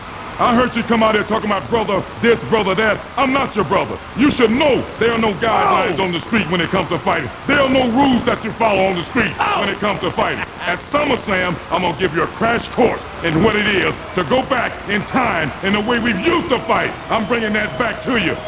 At times, it seemed as if even Simmons himself didn’t quite understand the character, as he mixed his ancient mystique with modern street lingo in goofy interviews like